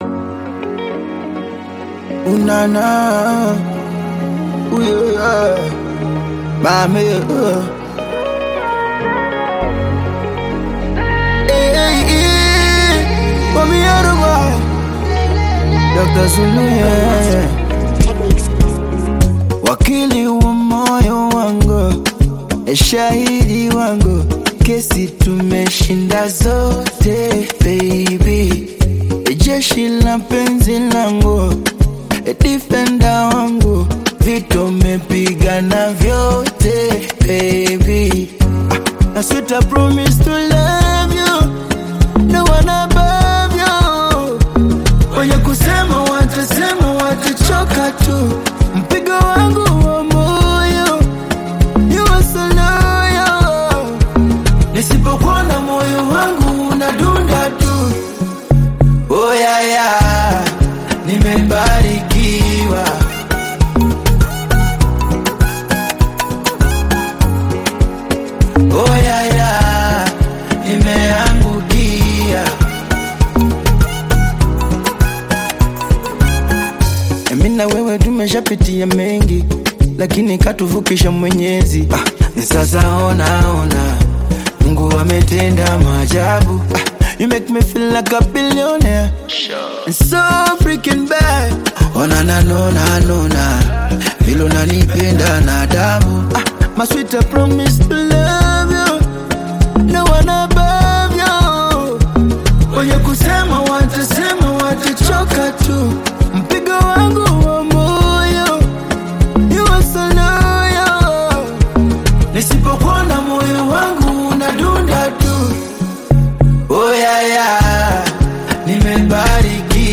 uplifting Afro-Pop/Afro-R&B single